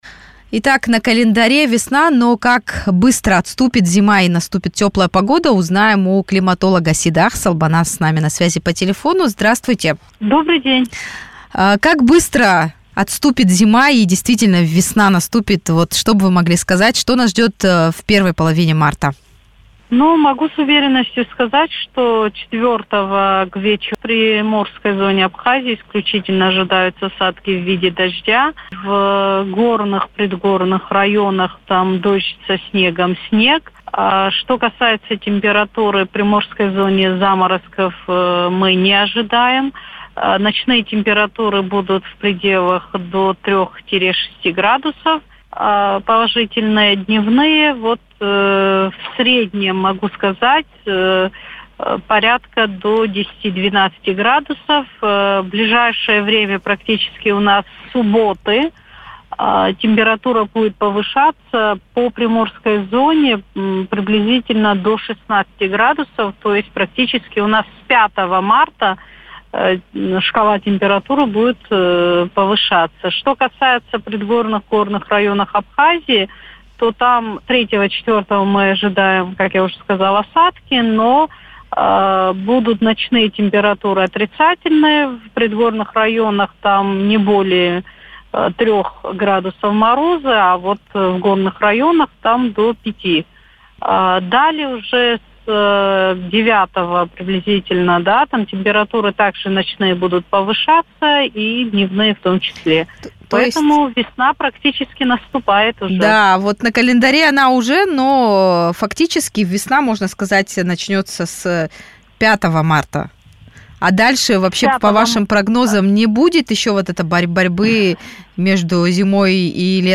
интервью радио Sputnik